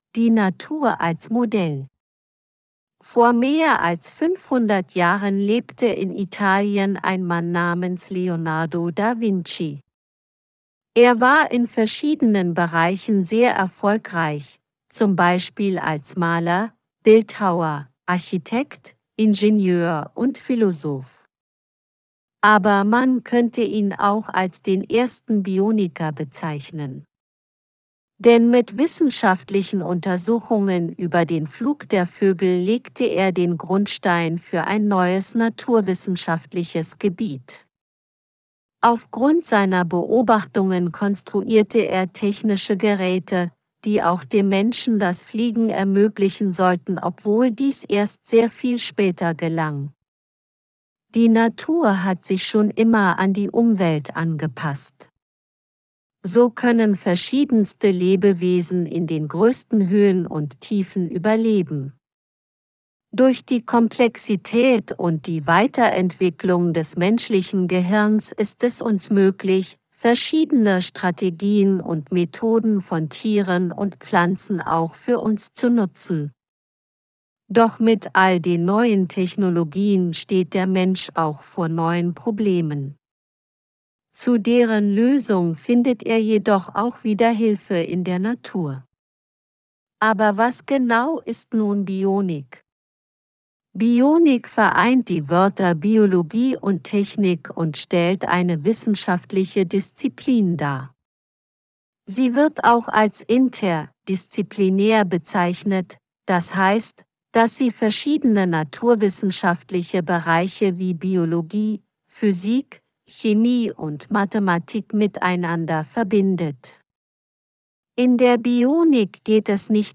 Hoerverstehen_audio_generiert.wav